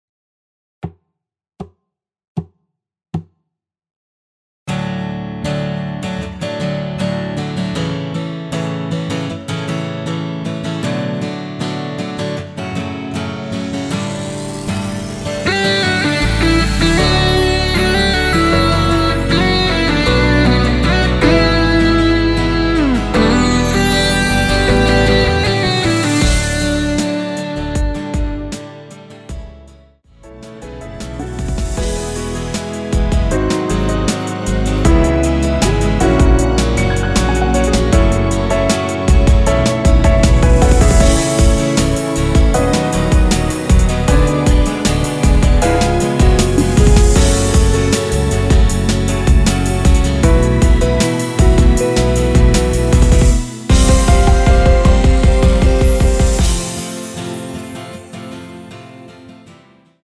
전주 없이 시작하는 곡이라서 노래하기 편하게 카운트 4박 넣었습니다.(미리듣기 확인)
원키에서(-2)내린 MR입니다.
앞부분30초, 뒷부분30초씩 편집해서 올려 드리고 있습니다.
중간에 음이 끈어지고 다시 나오는 이유는